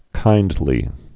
(kīndlē)